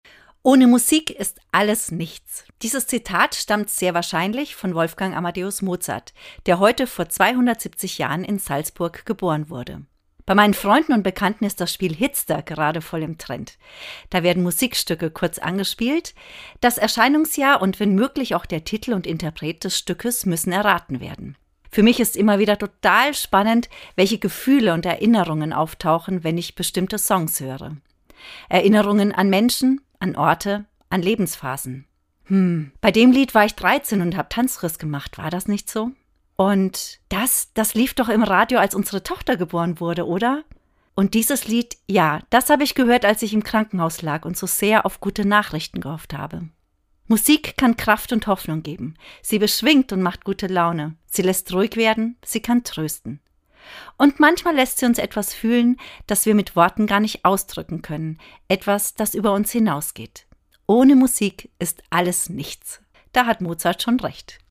Autorin und Sprecherin